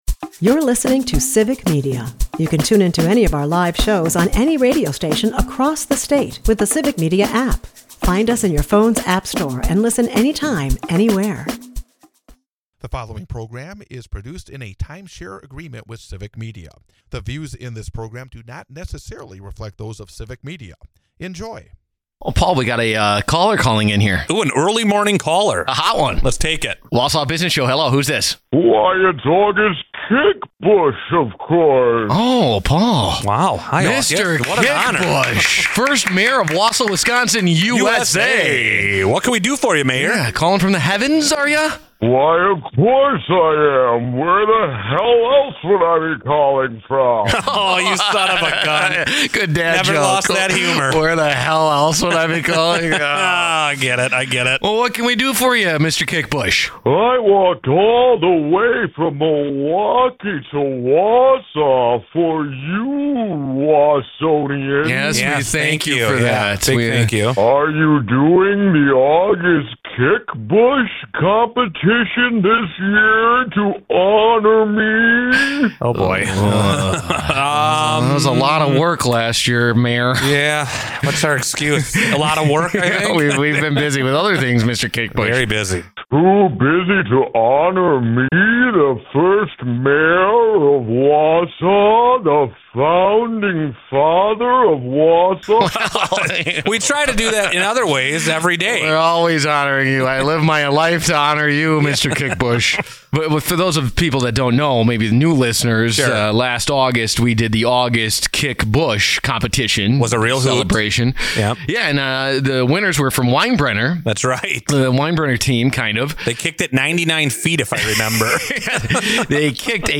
Plus, a Wausau Business Rumor is finally laid to rest. The Wausau Business Show is a part of the Civic Media radio network and airs Saturday from 8-9 am on WXCO in Wausau, WI.